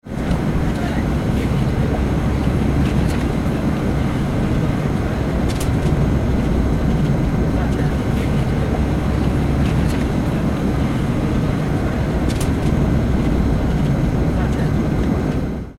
Airplane Boarding Sound Effect
A realistic airplane boarding sound effect with the hum of the aircraft and passengers talking before takeoff.
Airplane-boarding-sound-effect.mp3